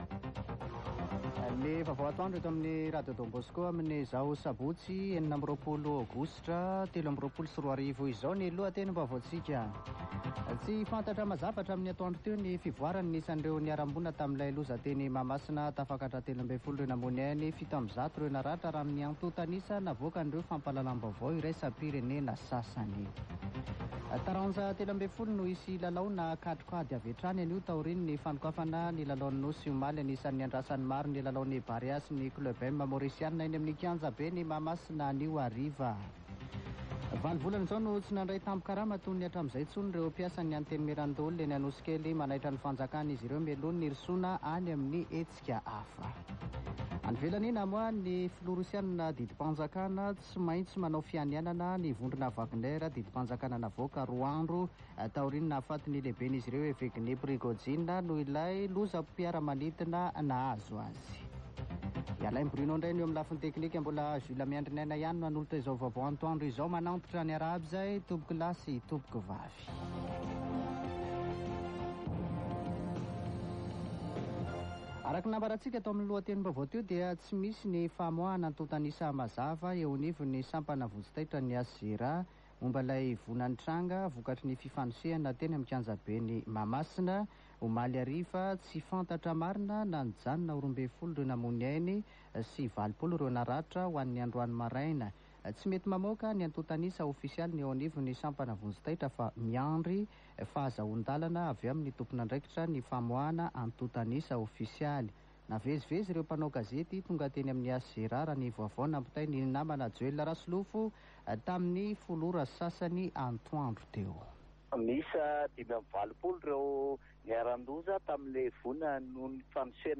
[Vaovao antoandro] Sabotsy 26 aogositra 2023